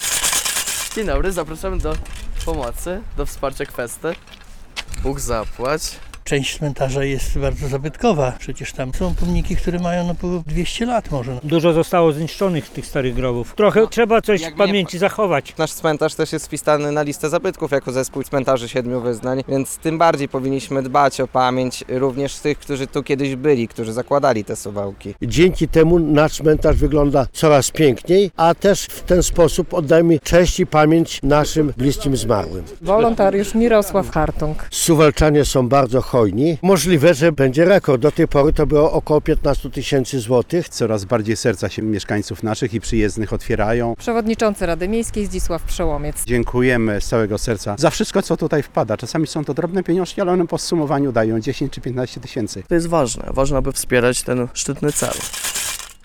Kwesta w Suwałkach - relacja